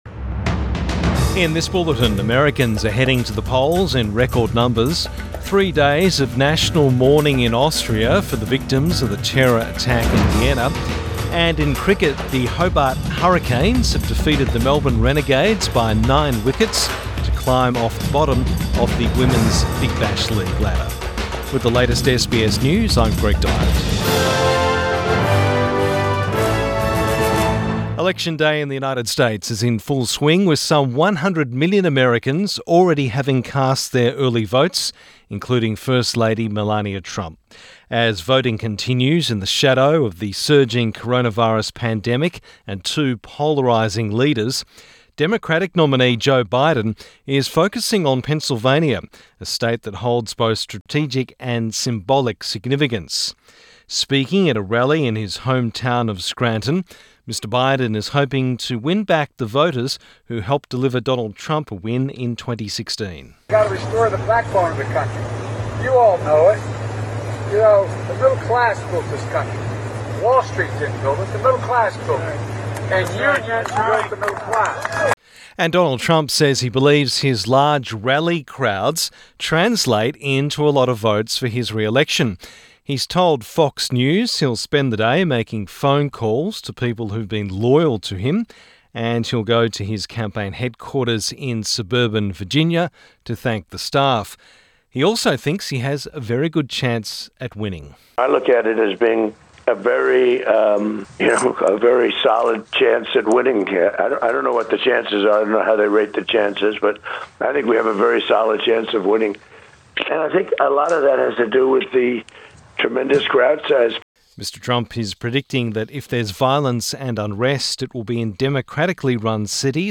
AM bulletin 4 November 2020